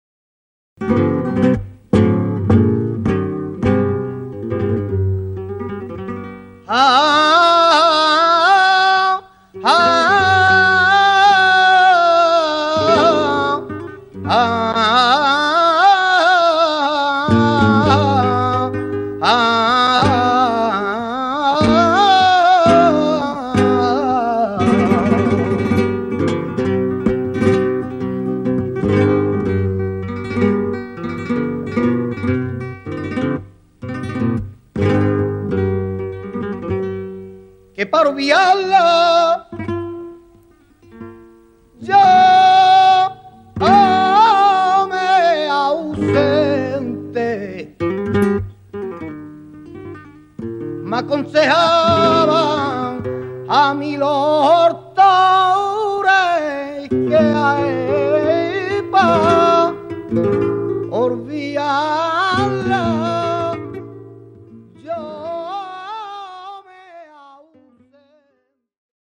FANDANGO (